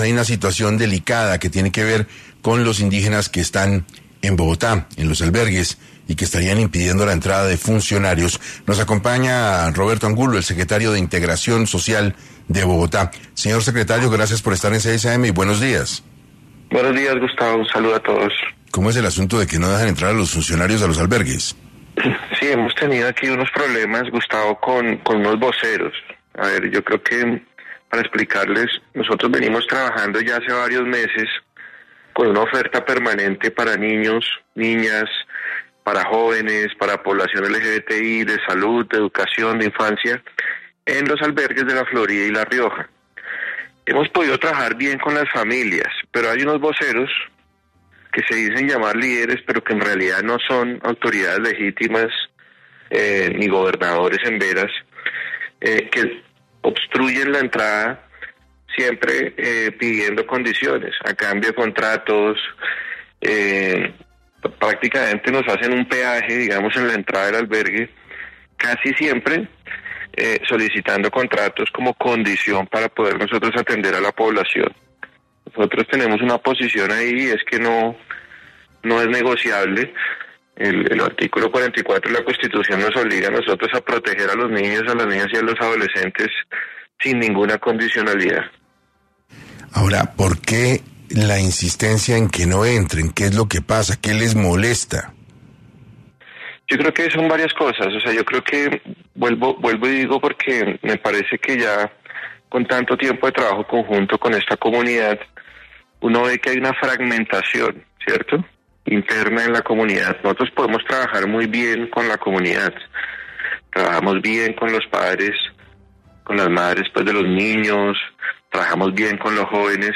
Hoy, en entrevista para 6AM, el secretario de Integración Social de Bogotá, Roberto Angulo, manifestó su preocupación, ya que algunos voceros Emberá no les están permitiendo desarrollar y cumplir sus funciones humanitarias a cabalidad.